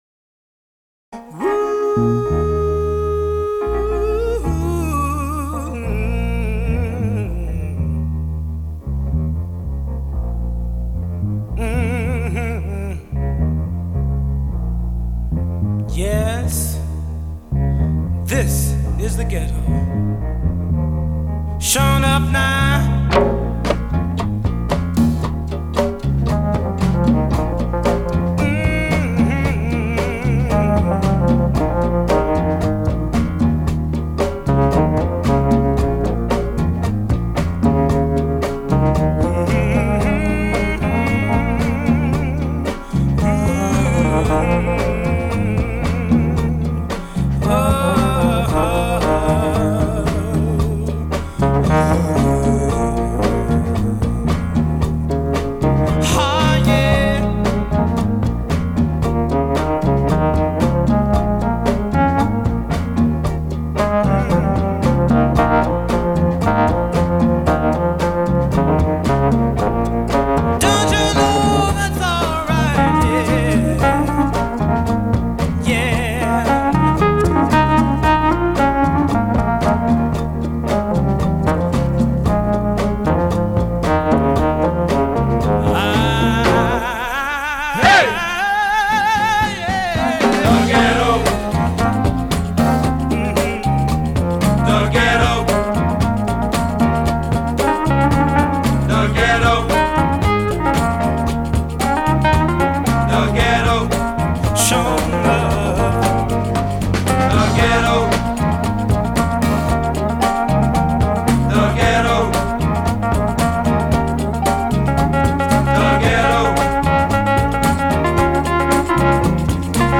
TEMPO : 111
Les chœurs interviennent, le clavier est en mode solo.
Clap sur 2 et 4
studio record Jan 04